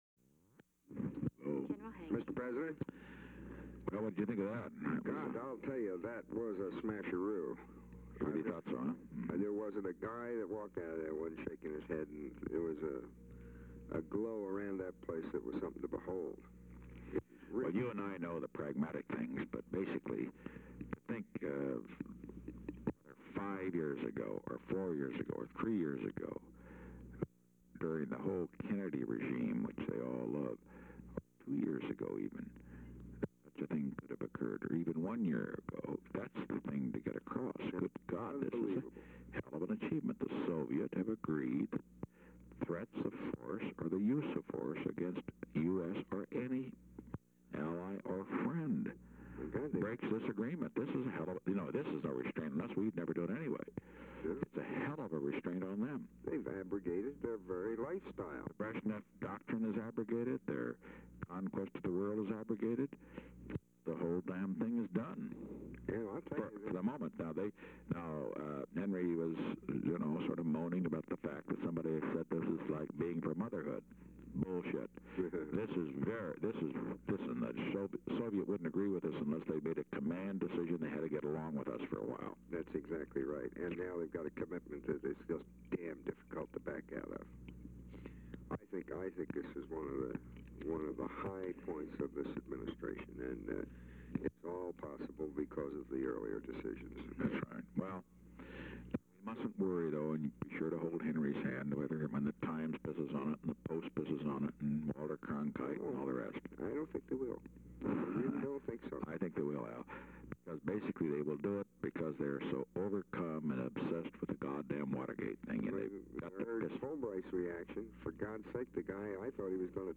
Location: White House Telephone
The President talked with Alexander M. Haig, Jr.